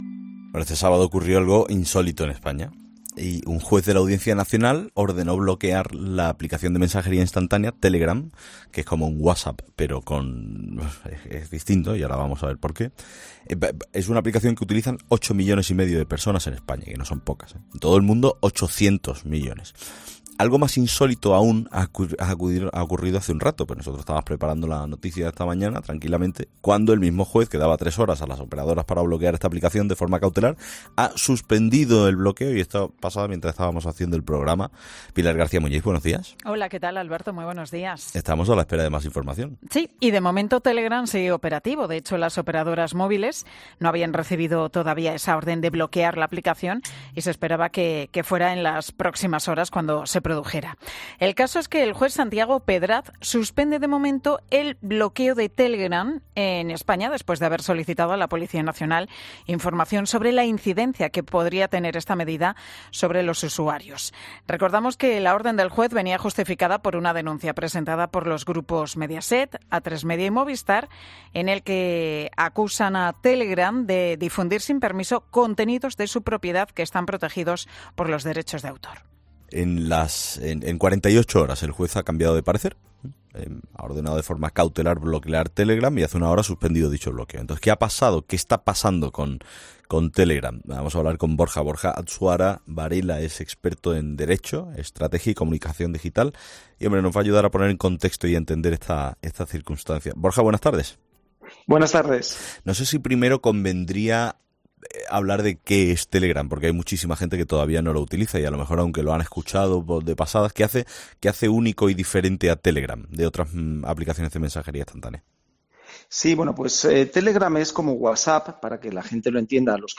Un experto en Comunicación Digital desgrana todas las claves del caso de Telegram